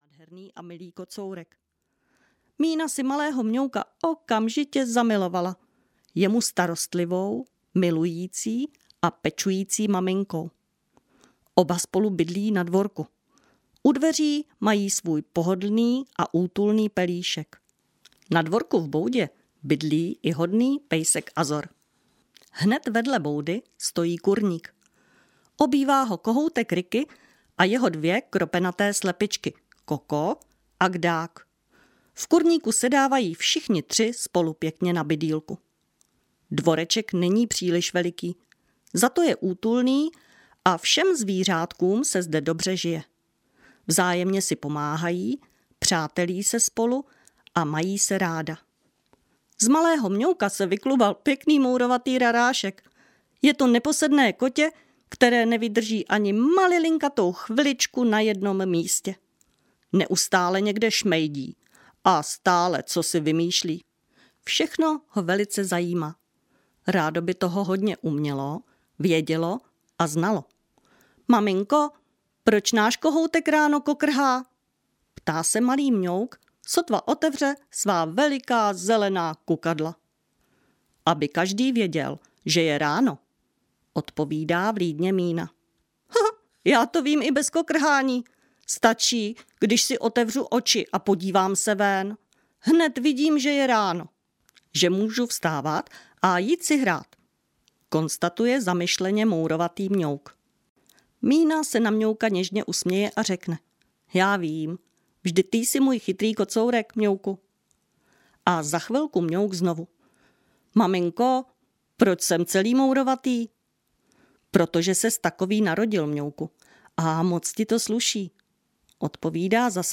Nezbedné příhody malého Mňouka audiokniha
Ukázka z knihy